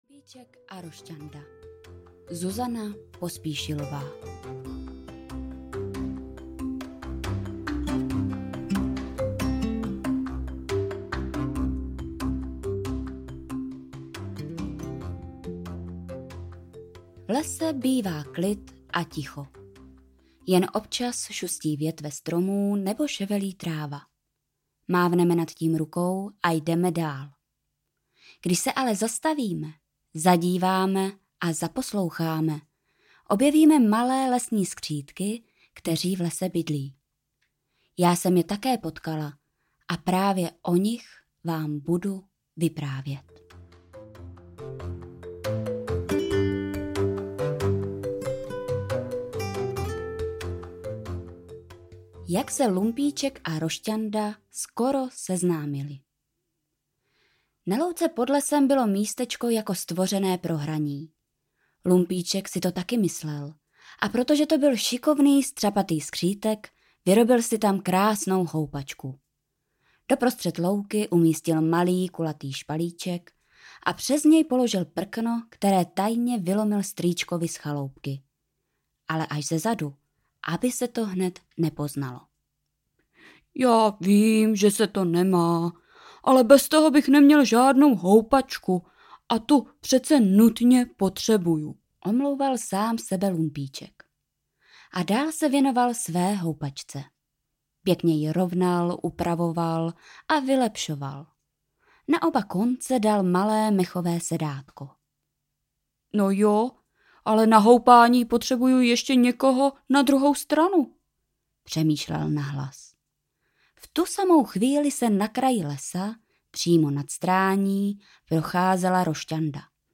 Lumpíček a Rošťanda audiokniha
Ukázka z knihy